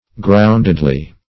Meaning of groundedly. groundedly synonyms, pronunciation, spelling and more from Free Dictionary.
Search Result for " groundedly" : The Collaborative International Dictionary of English v.0.48: Groundedly \Ground"ed*ly\, adv. In a grounded or firmly established manner.